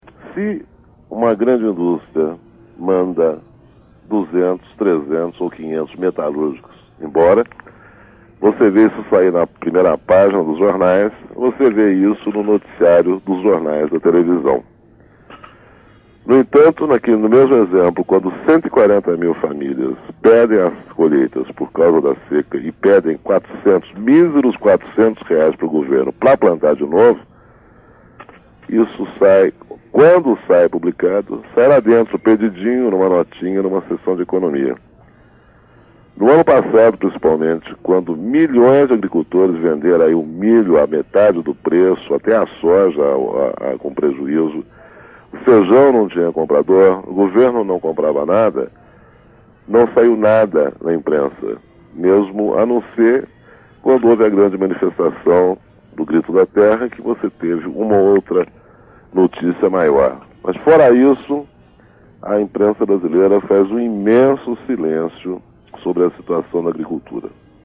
Em sua argumentação, gravada no programa A Voz da Contag , retomou o exemplo sobre os produtores gaúchos que perderam as plantações por conta da seca e criticou a cobertura da imprensa sobre o tema.